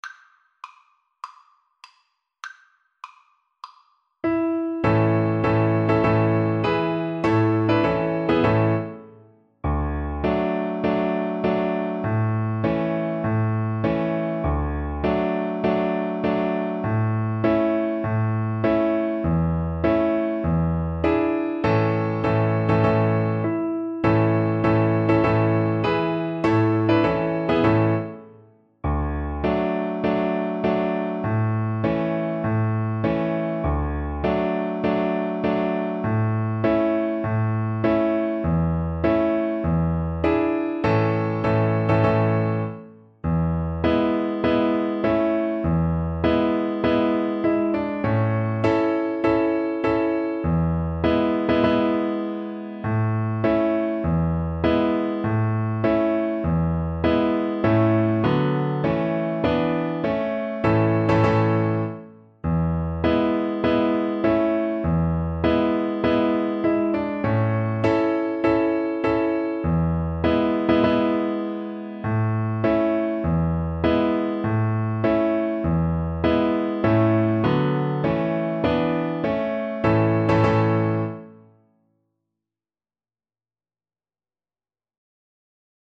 A major (Sounding Pitch) (View more A major Music for Violin )
4/4 (View more 4/4 Music)
Classical (View more Classical Violin Music)